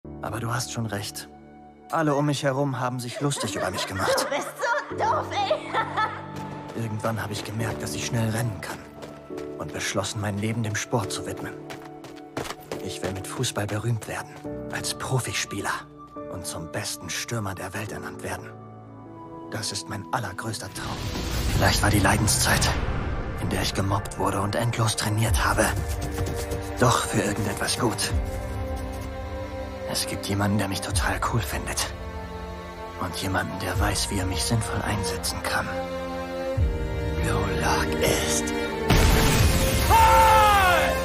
hell, fein, zart, markant, sehr variabel
Jung (18-30)
Commercial (Werbung)